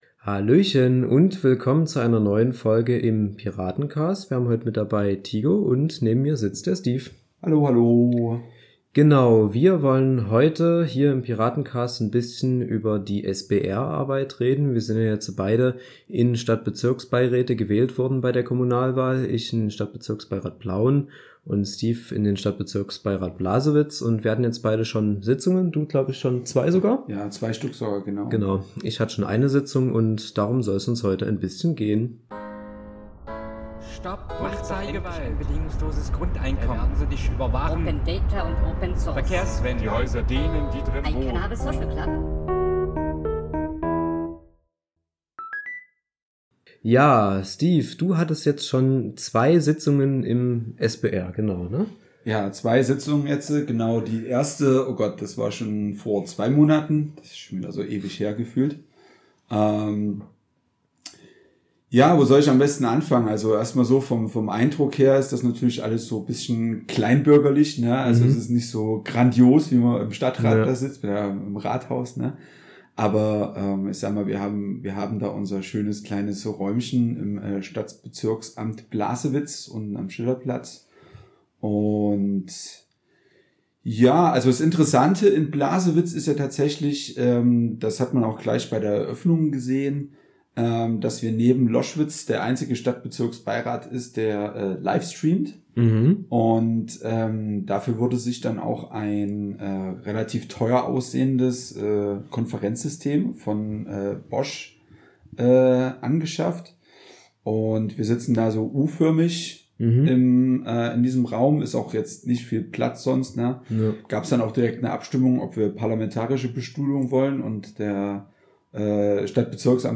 Tigo und Steve reden als frisch gewählte Stadtbezirksbeiräte über ihre ersten Eindrücke und Inhalte aus den ersten Sitzungen.